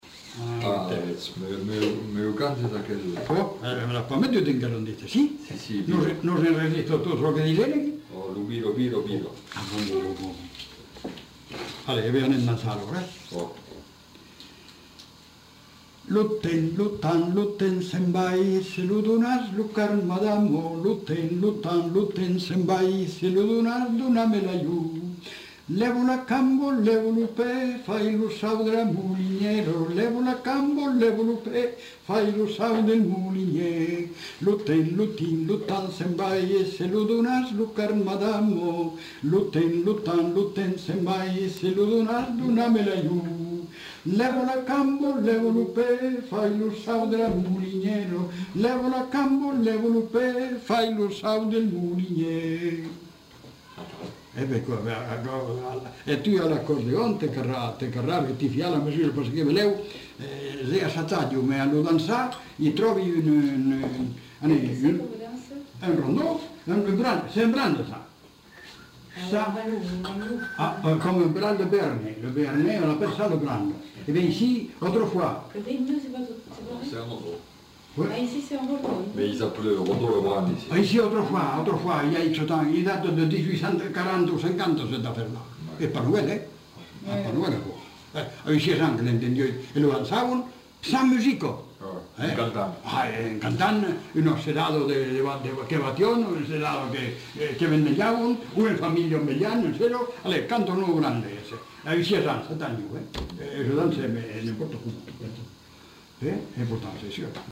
Aire culturelle : Haut-Agenais
Genre : chant
Effectif : 1
Type de voix : voix d'homme
Production du son : chanté
Danse : rondeau